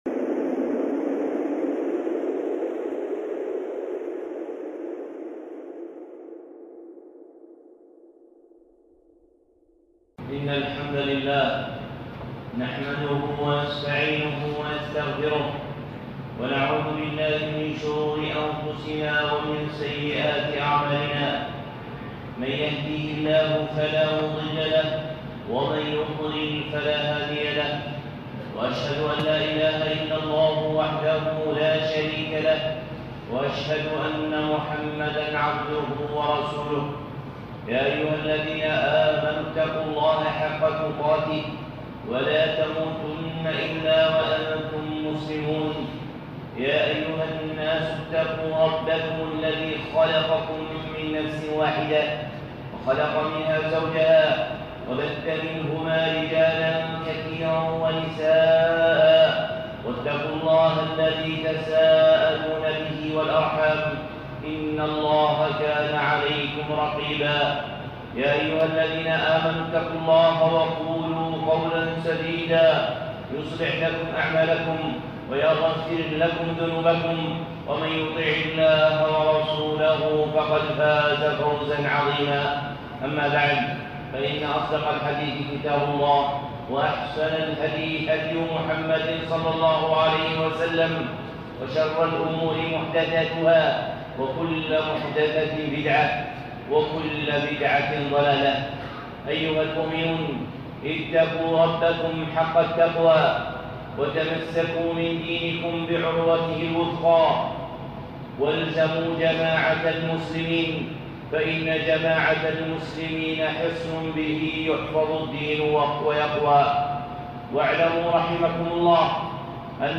خطبة (لا يستوي المؤمنون والكافرون)